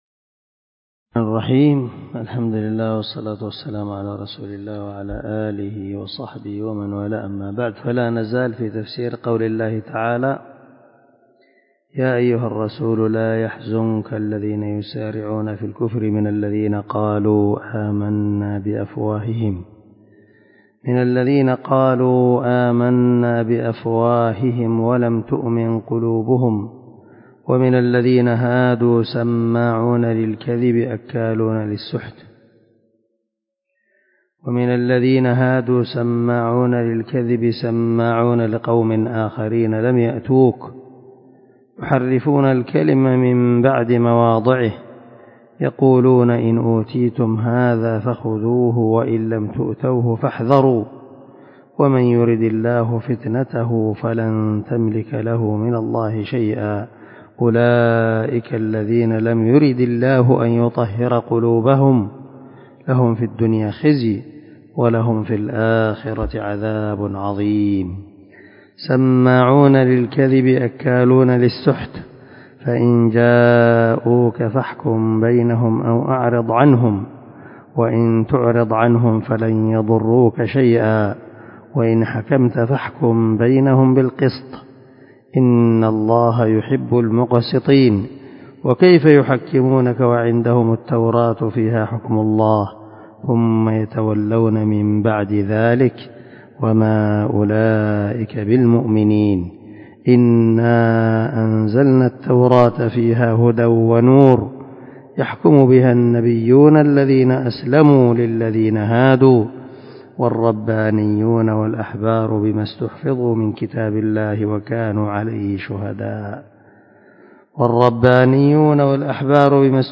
362الدرس 29 تابع تفسير آية ( 41 - 44 ) من سورة المائدة من تفسير القران الكريم مع قراءة لتفسير السعدي